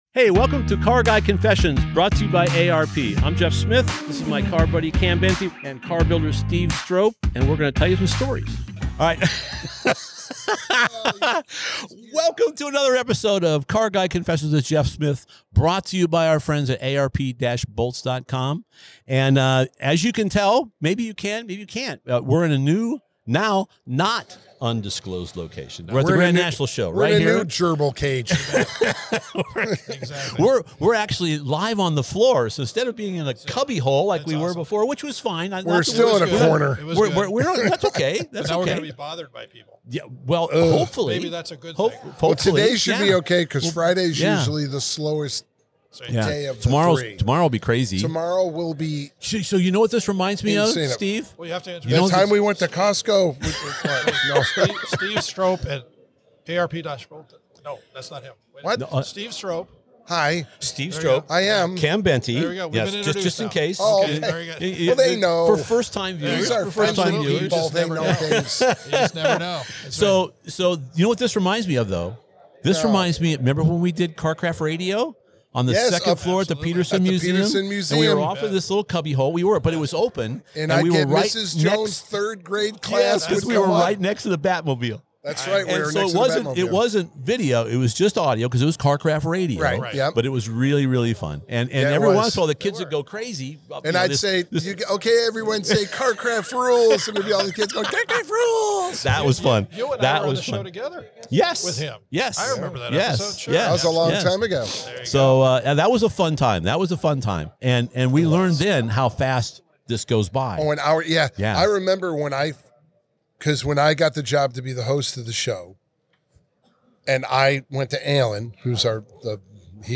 Recorded at the Grand National Roadster Show.